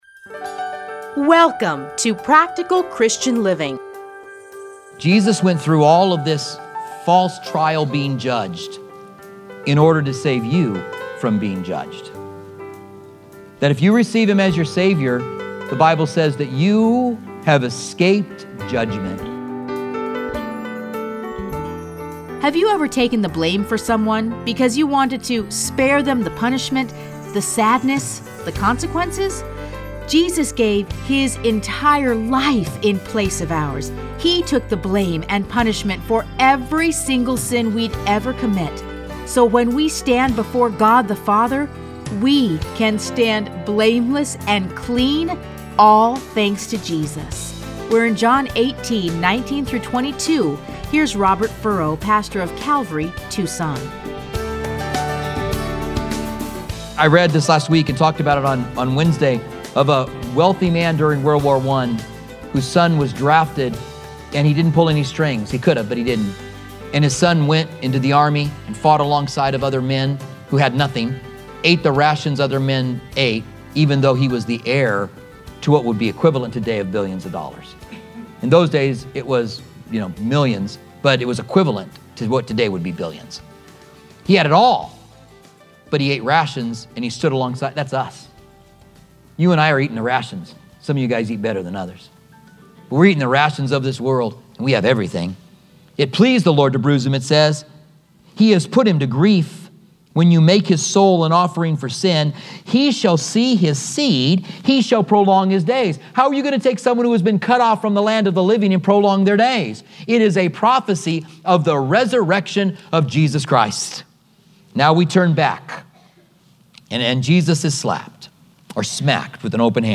Listen to a teaching from John 18:19-22.